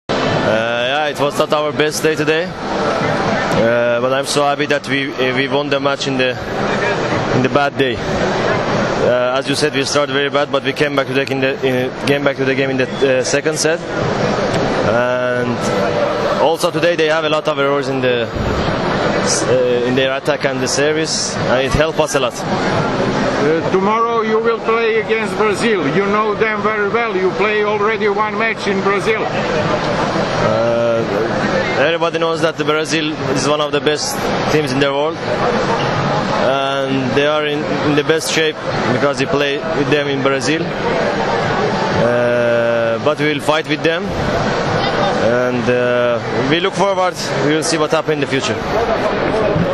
IZJAVA SAIDA MARUFA